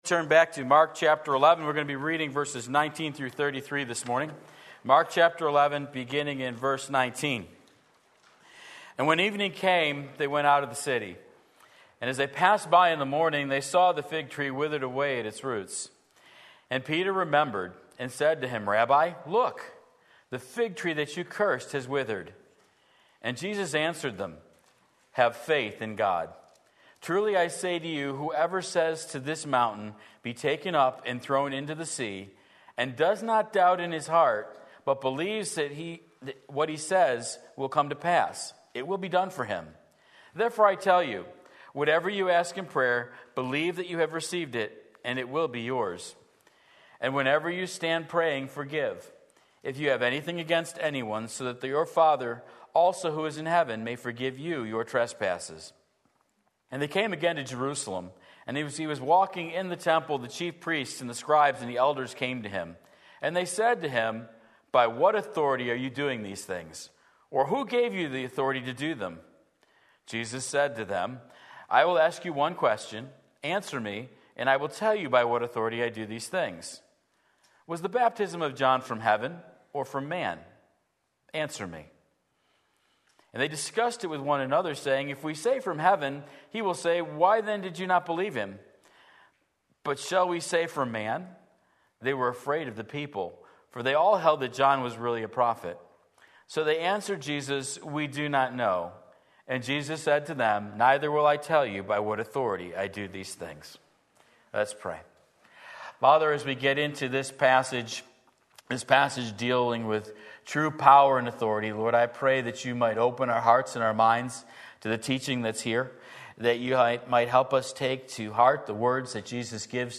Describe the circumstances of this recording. Real Power and Authority Mark 11:19-33 Sunday Morning Service